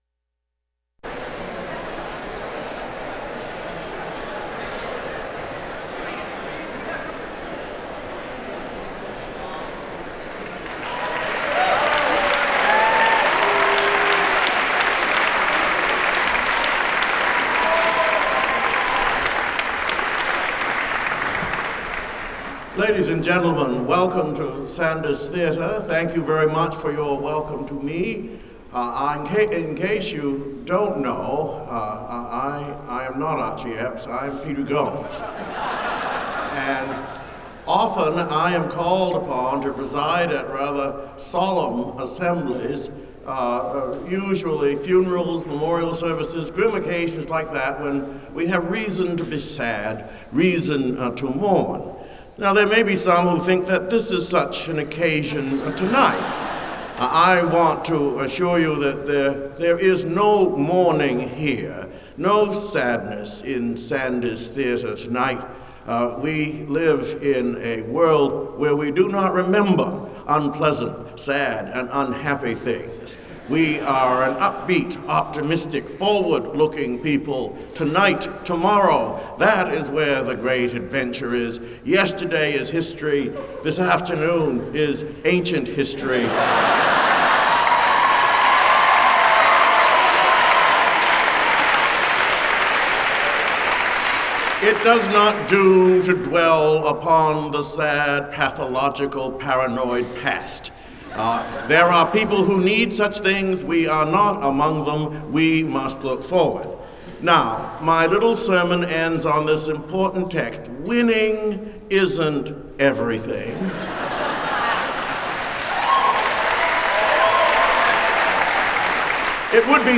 The Kroks of 2001 and 2004 also asked him to sing at their Fall Jams with Yale groups – the Whiffs in 2001 and the Alley Cats in 2004.